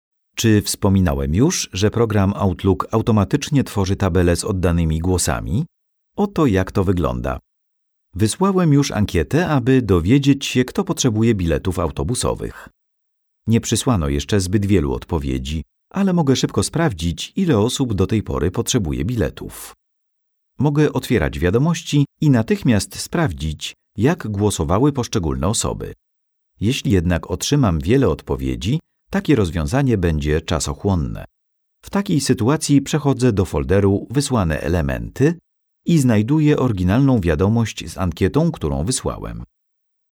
over 15 years of experience as a voice talent and actor. recording in professional studio (not home one) so the highest quality sound is assured.
strong voice, Polish voiceover artist, Polish voice talent
Sprechprobe: eLearning (Muttersprache):